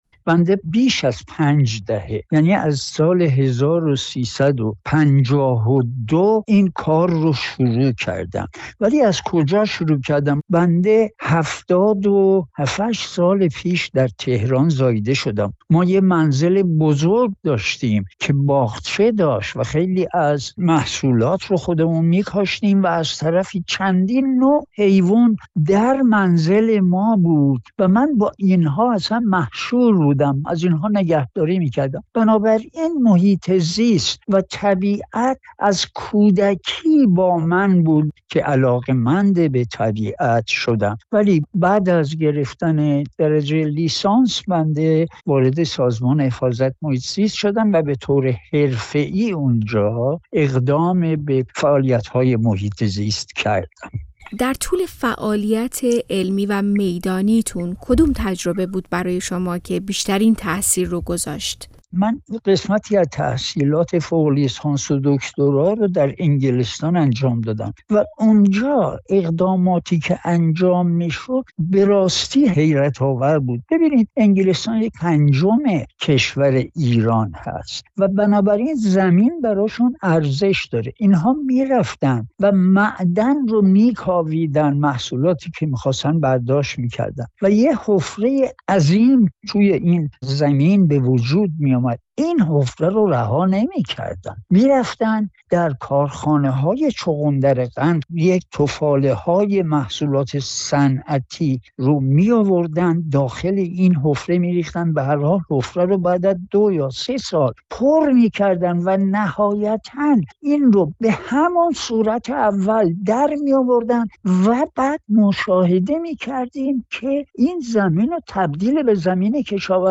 دکتر اسماعیل کهرم به پاس یک عمر تلاش در عرصه حفاظت از محیط‌ زیست ایران مورد تقدیر قرار گرفته و جایزه مهرگان علم را دریافت کرد. او در گفت‌وگو با رادیوفردا بار دیگر در مورد بحران بی‌آبی و نابودی تالاب‌های کشور هشدار داد.